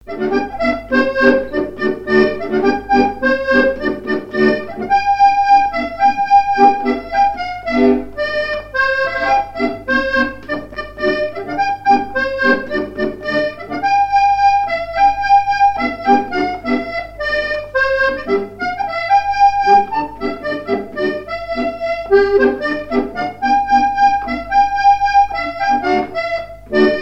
Mémoires et Patrimoines vivants - RaddO est une base de données d'archives iconographiques et sonores.
airs de danse à l'accordéon diatonique
Pièce musicale inédite